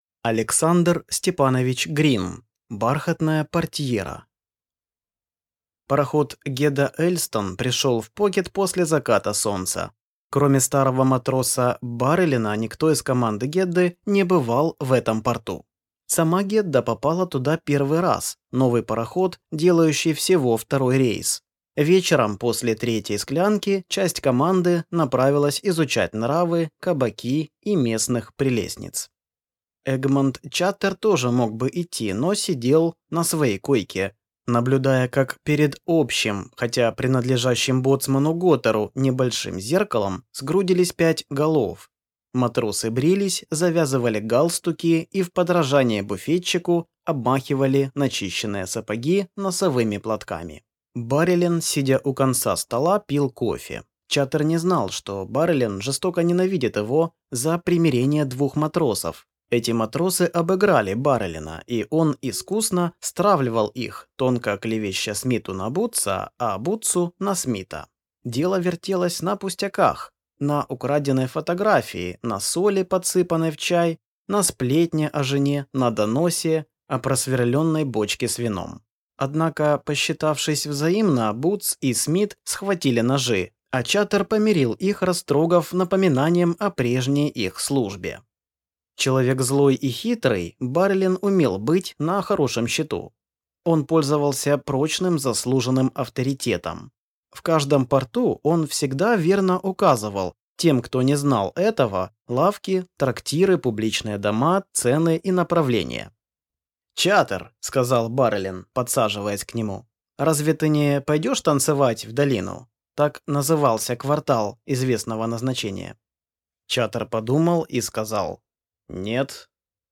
Aудиокнига Бархатная портьера